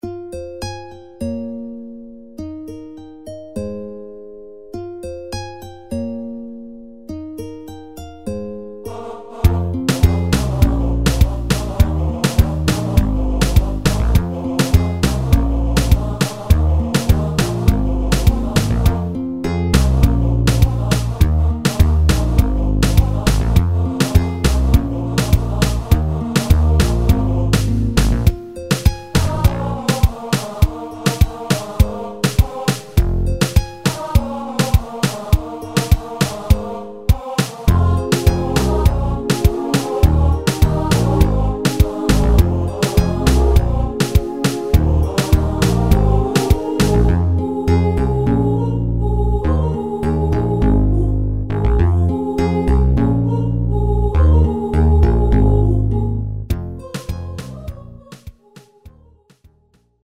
MIDI Multitrack
Bajo – 208 notas
Synth Bass – 355 notas
Guitarra Nylon – 420 notas
Warm Pad – 156 notas
Voz Femenina – 521 notas
Voz Masculina – 348 notas
Percusión – 1398 notas